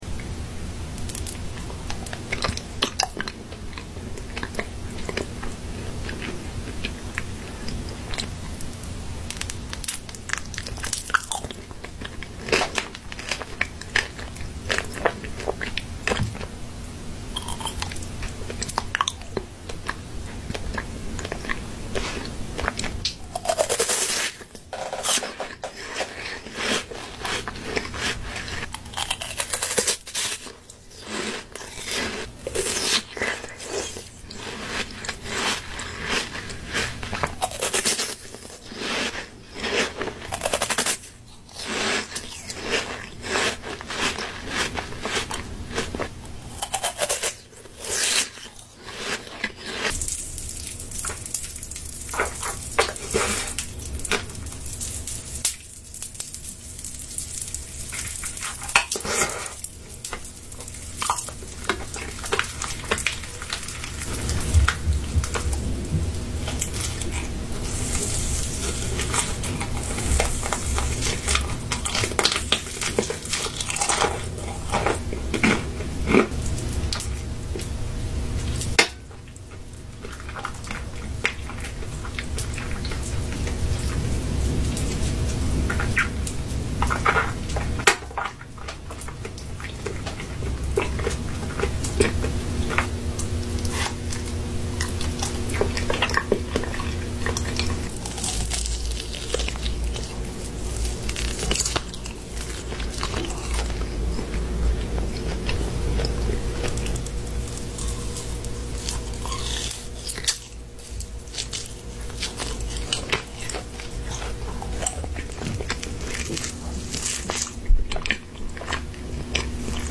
SLEEP SOUND asmr eating